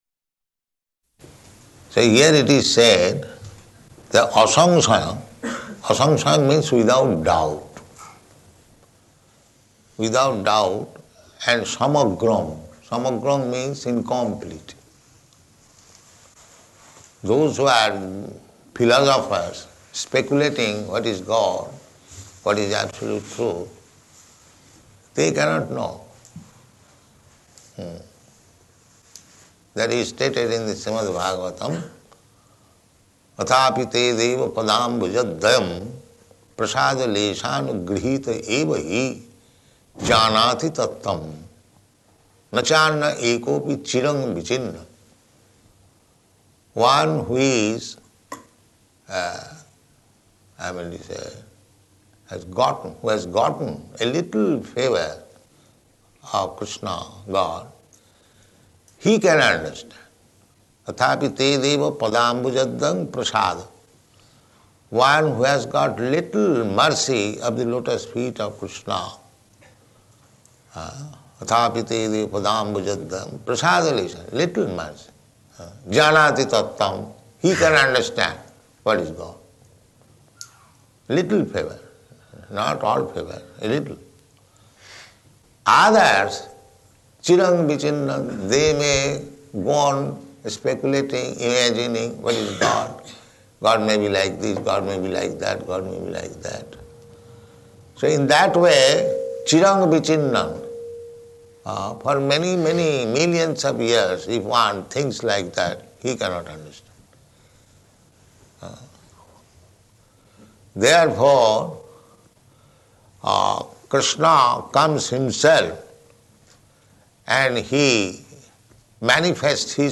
Bhagavad-gītā 7.1 --:-- --:-- Type: Bhagavad-gita Dated: June 29th 1974 Location: Melbourne Audio file: 740629BG.MEL.mp3 Prabhupāda: So here it is said that asaṁśayam.